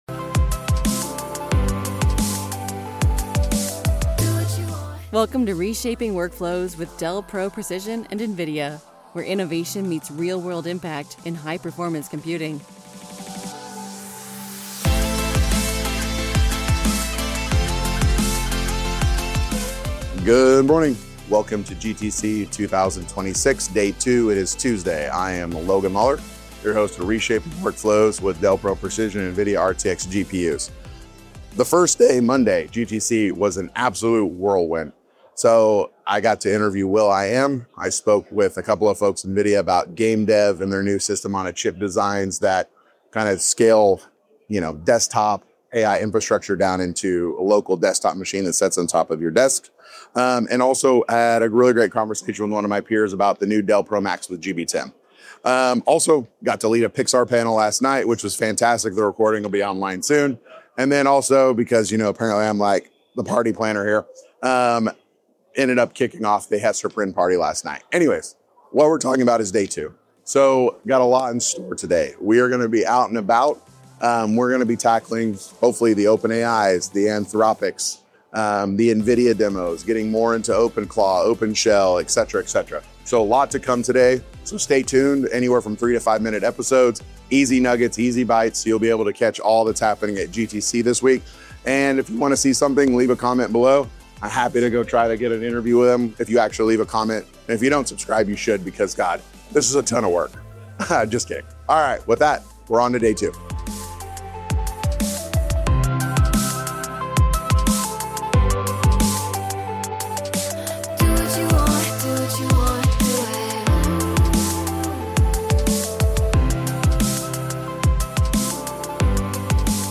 Live from GTC: Day 2 Preview